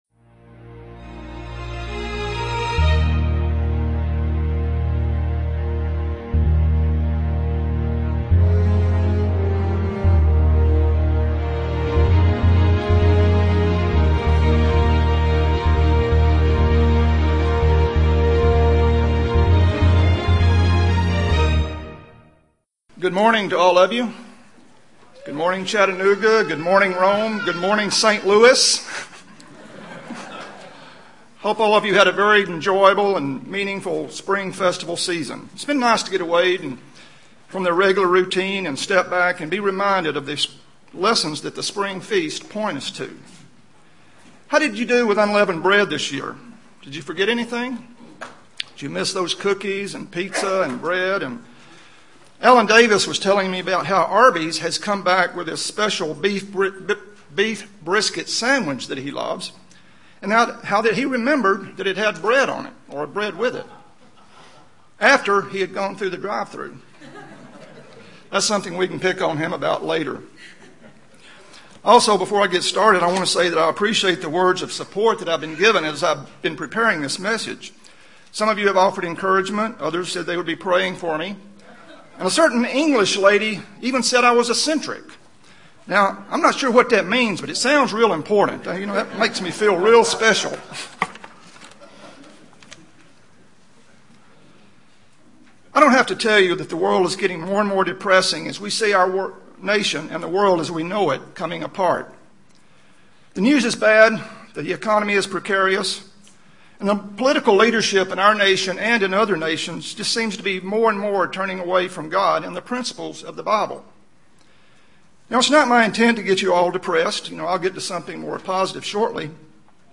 Given in Chattanooga, TN
UCG Sermon Studying the bible?